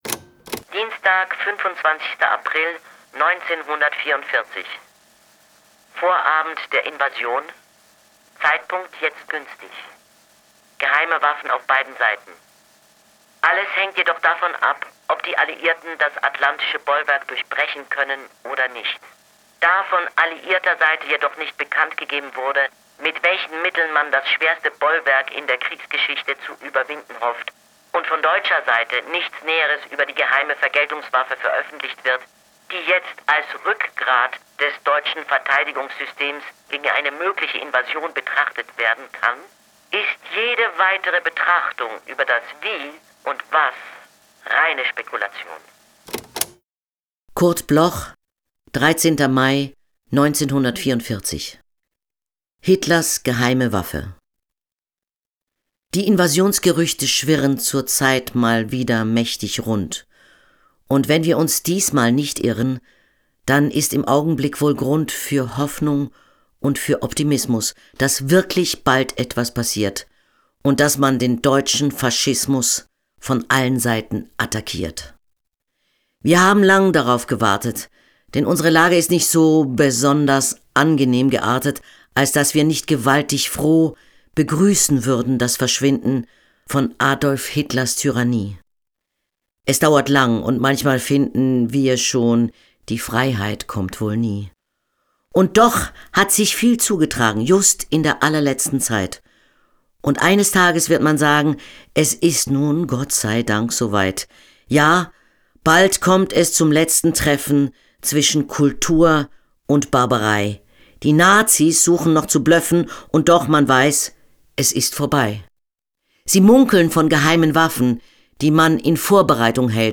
vorgetragen